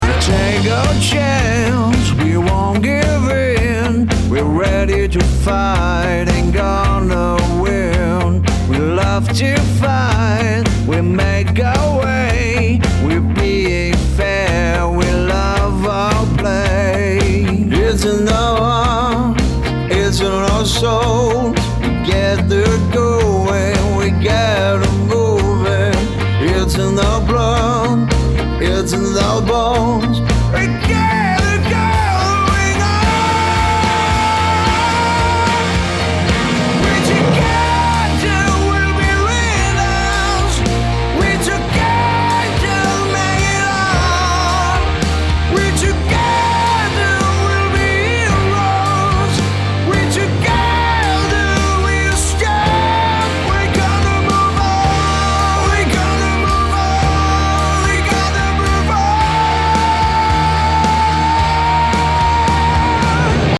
In einem der letzten Projekte sollte ich auf Basis einer Gesangslinie einen Song im Stile einer Rockballade mit Musicalanleihen kreieren.
Zudem hatte sich der Auftraggeber eine rockige, sehr ausdrucksstarke Männerstimme für die Umsetzung gewünscht, die sich natürlich auch fand.
rockballade.mp3